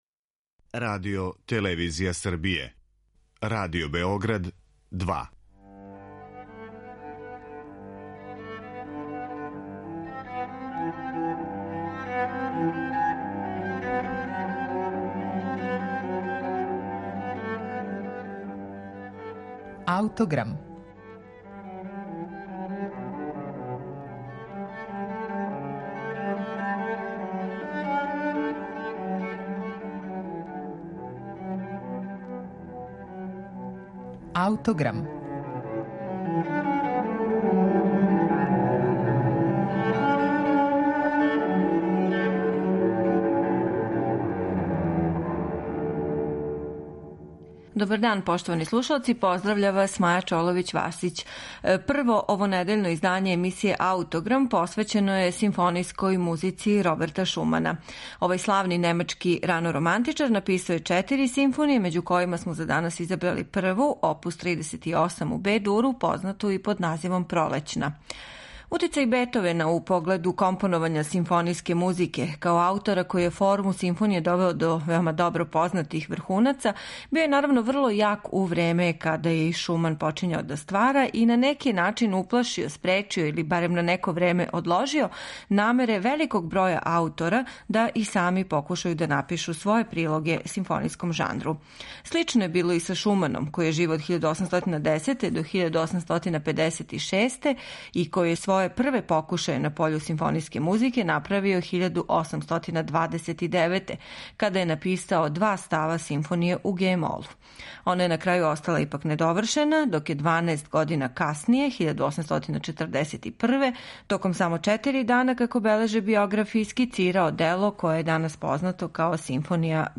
Снимак Симфонијског оркестра Југозападног радија, којим диригује Марек Јановски.